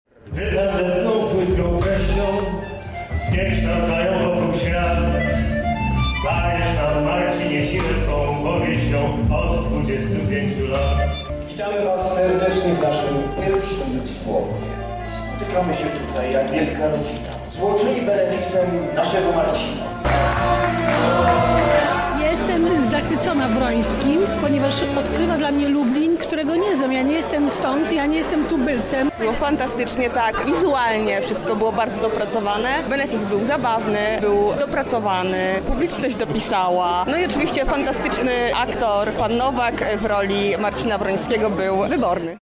Wydarzenie zgromadziło wielu fanów oraz czytelników powieści kryminalnych.
Nasza reporterka uczestniczyła w benefisie i zapytała uczestników o wrażenia.